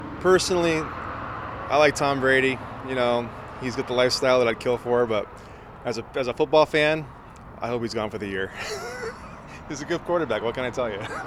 NON-PATRIOTS FAN AT LUDLOW, MA REST STOP WHO’S GLAD BRADY IS OUT FOR FOUR GAMES BECAUSE HE IS SO GOOD.
2-BRADY-MOS-DALLAS-FAN-AT-LUDLOW-MA-REST-STOP-HAPPY-TO-SEE-BRADY-OUT-OF-COMMISSION-FOR-FOUR-GAMES.mp3